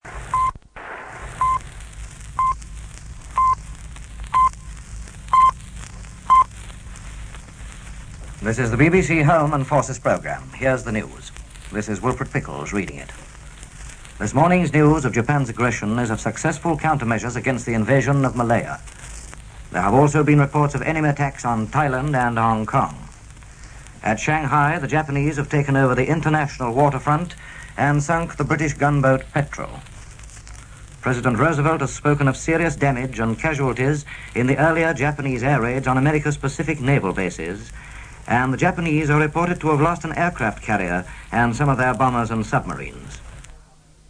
Wilfred Pickles was a Yorkshireman; and proud of it. On the BBC North Regional Programme, then on the Home Service, he was said to be the first newsreader not to speak with Received Pronunciation. It is easy to see how his slightly warmer approach comforted in the dark days of War; and, it was said, was 'a deliberate attempt to make it more difficult for Nazis to impersonate BBC broadcasters'.
Here he is on 8th December, 1941 in full flow in one of his BBC News bulletins. Notice too the days before the final 'pip' was elongated.